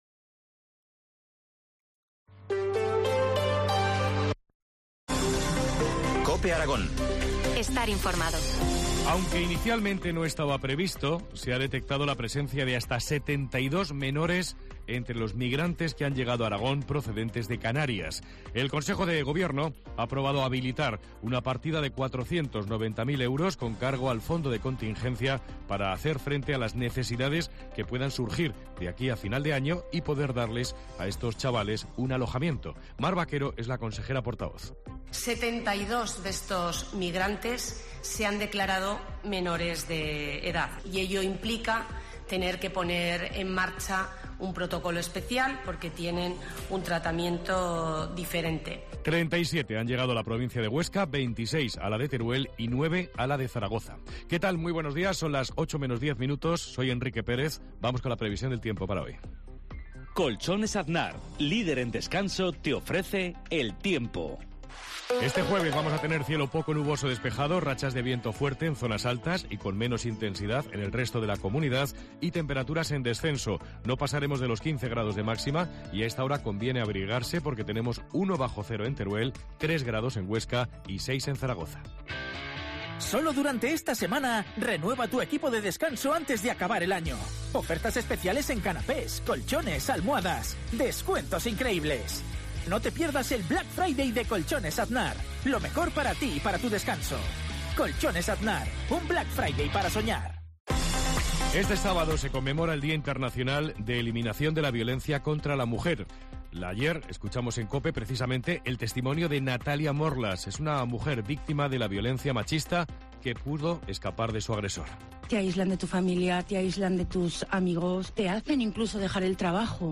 Informativo local Herrera en COPE Aragón-Huesca 07.50h